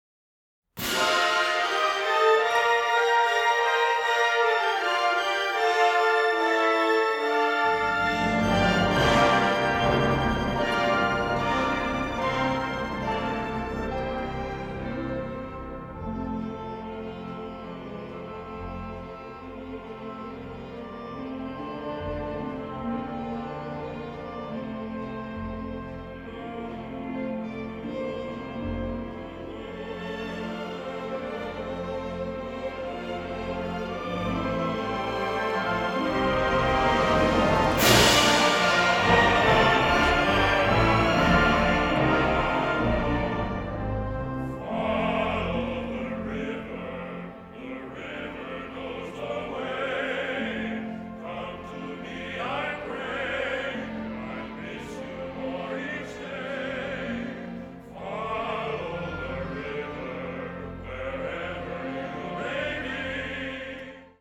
baritone.